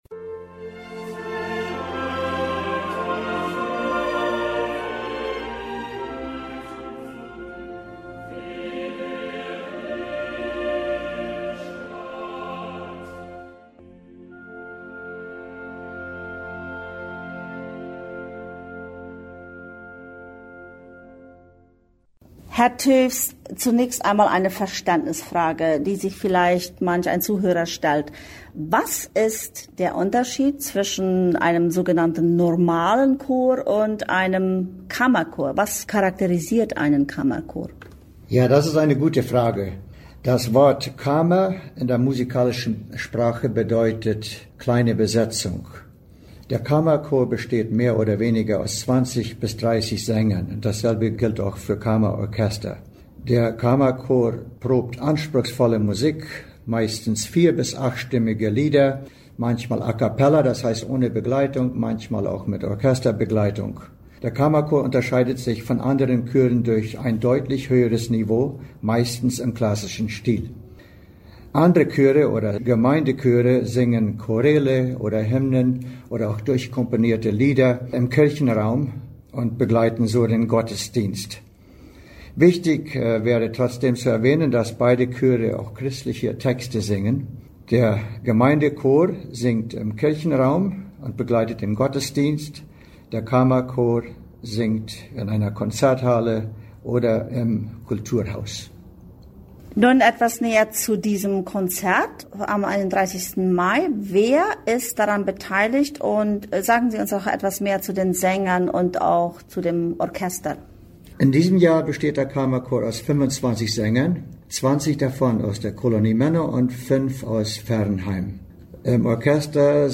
Kammerchor und Kammerorchester Konzert